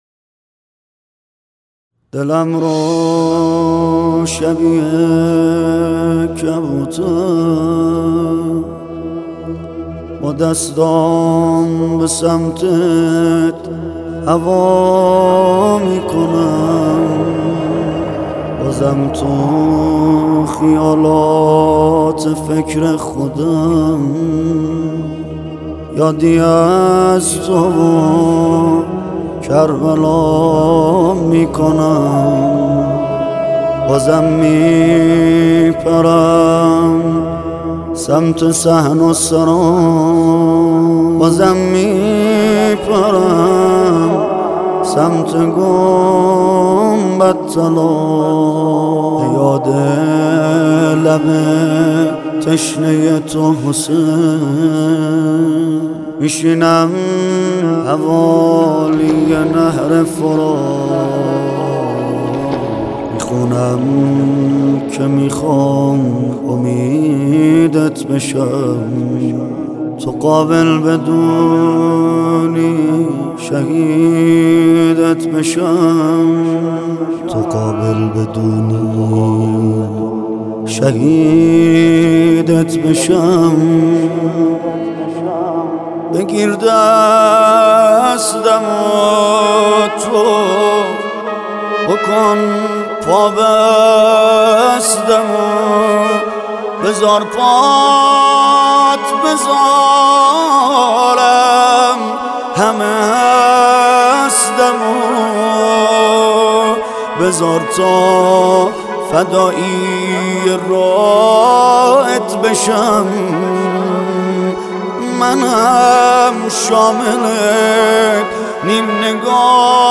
نواهنگی